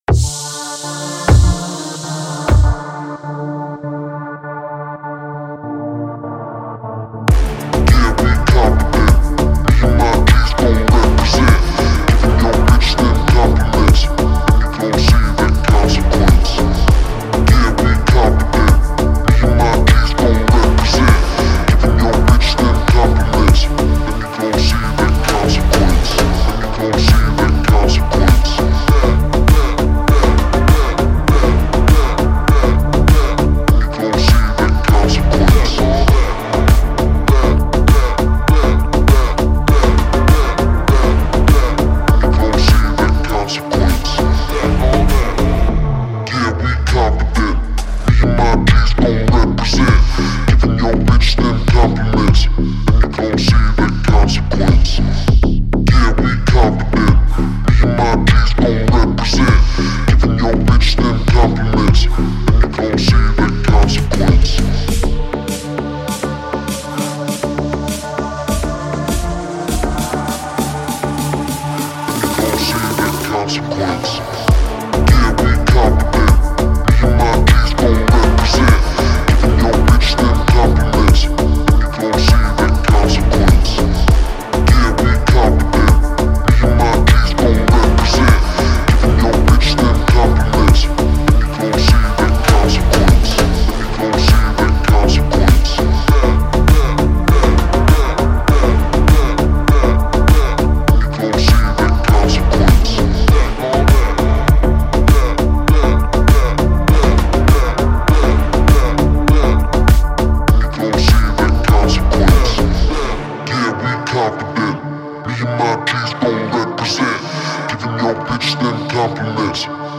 который сочетает в себе элементы попа и инди.